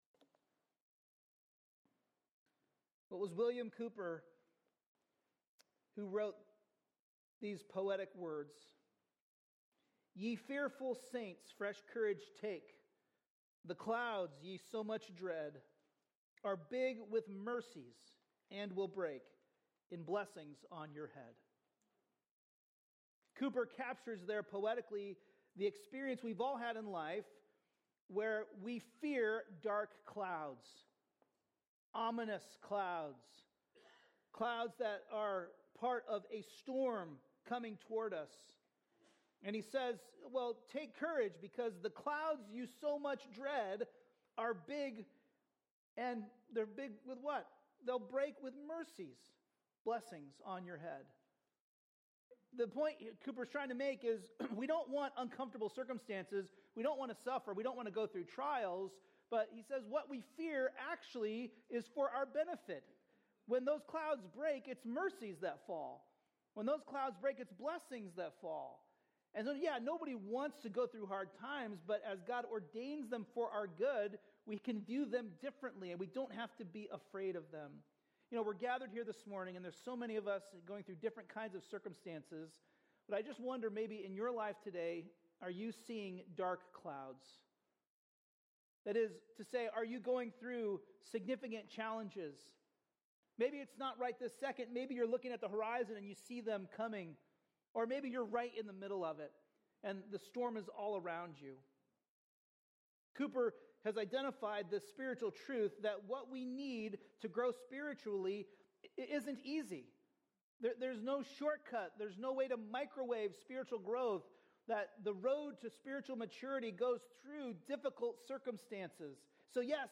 A message from the series "Ezra/Nehemiah." In Nehemiah 11:1 - 12:26, we learn that God restores us to live in holiness with Him for eternity and He doesn't just call to holiness, He makes us holy through Christ.